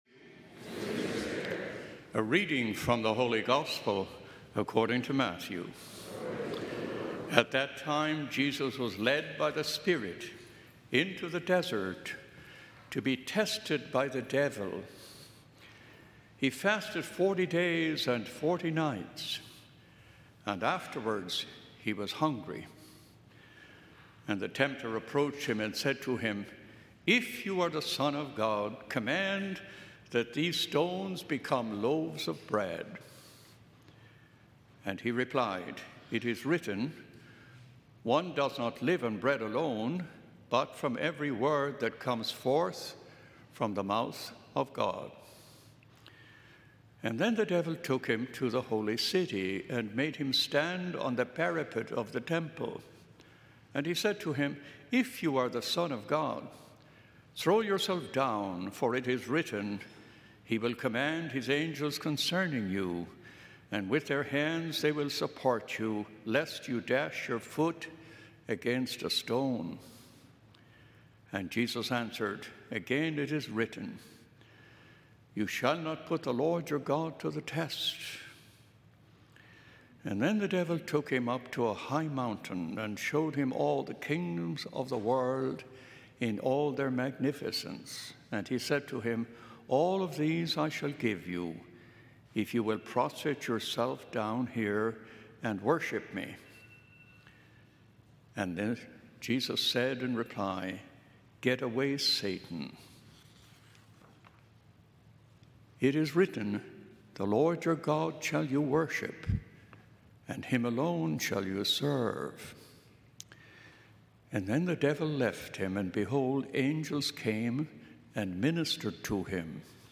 First Sunday of Lent, February 26, 2023, 9:30 am Mass